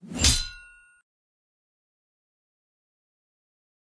Creature_Sounds-Sword_Hit_2.ogg